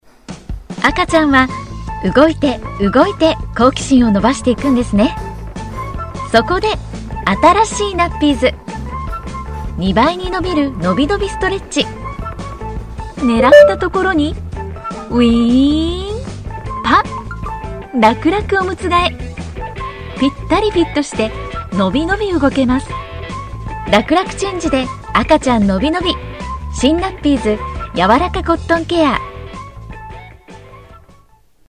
女日106 日语 甜美 全能 广告 激情激昂|娓娓道来|积极向上|时尚活力|神秘性感|亲切甜美|素人